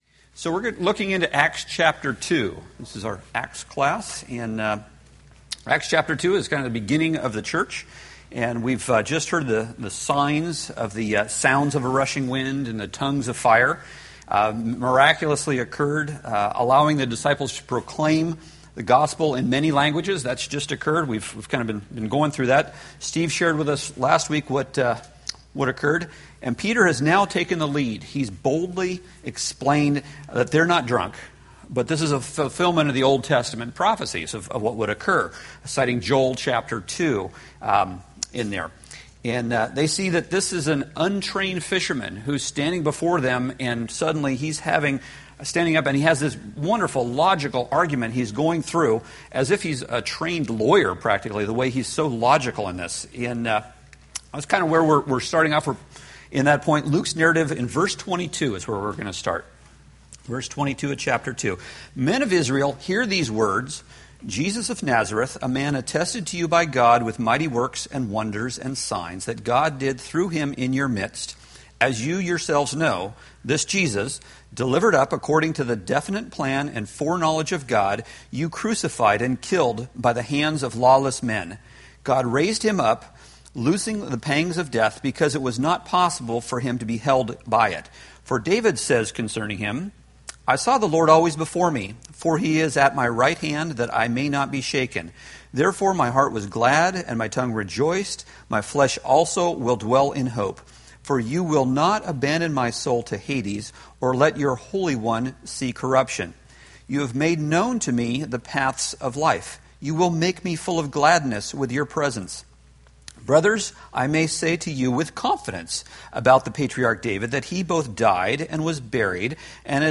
Date: Dec 1, 2013 Series: Acts Grouping: Sunday School (Adult) More: Download MP3